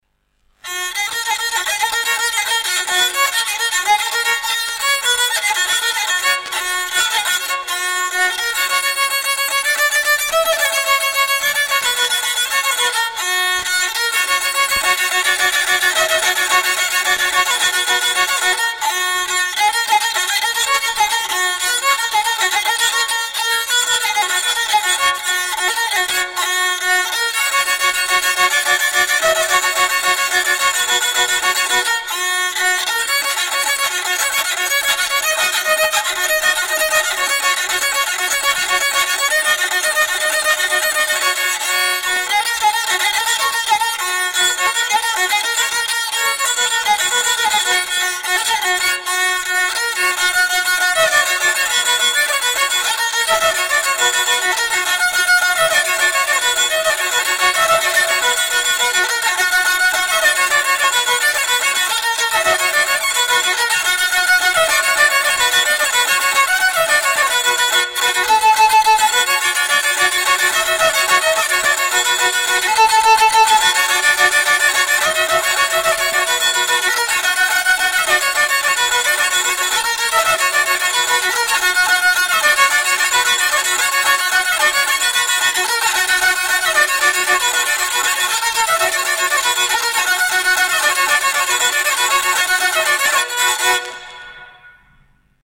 Rabel, zanfona y sonajas